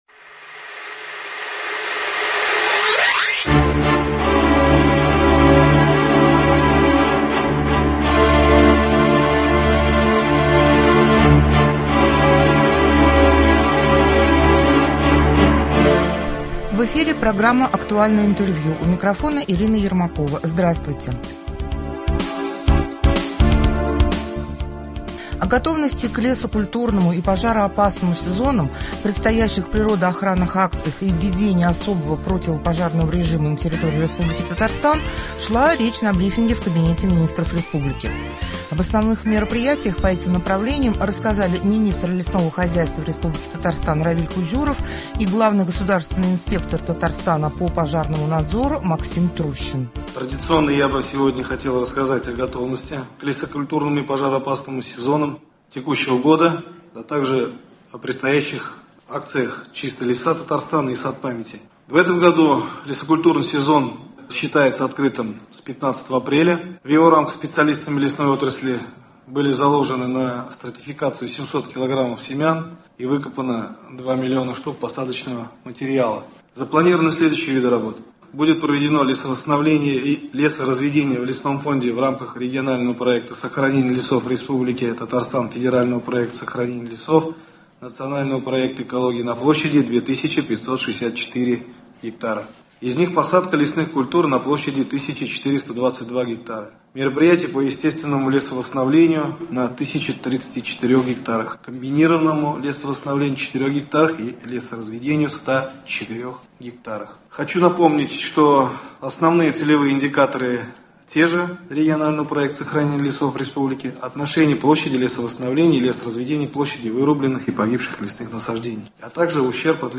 Актуальное интервью (17.04.24) | Вести Татарстан
О готовности к лесокультурному и пожароопасному сезонам, предстоящих природоохранных акциях и введении особого противопожарного режима на территории Республики Татарстан шла речь на брифинге в Кабинете министров республики.
Об основных мероприятиях по этим направлениям рассказали министр лесного хозяйства РТ Равиль Кузюров и Главный государственный инспектор Республики Татарстан по пожарному надзору Максим Трущин.